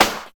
34 SNARE 4.wav